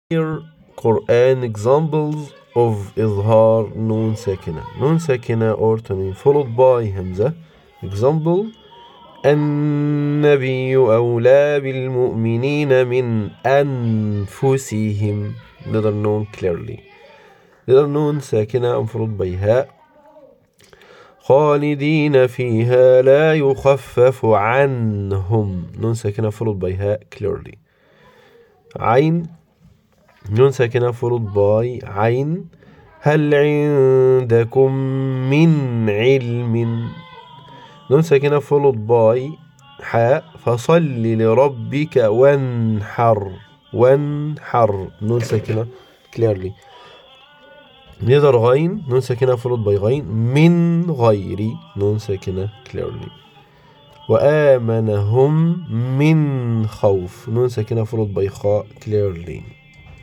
In Tajweed, the “n” sound of Noon Sakinah is pronounced fully and clearly, with no nasal twang or merging into the next letter.
Clear Quran Examples of Izhar Noon Sakinah
Pronounce it as “min ‘anfusihim” ,clear “n.”
“Min ghayr” that “n” pops before the raspy ghayn.
clear-quran-Examples-of-noon-sakinah.mp3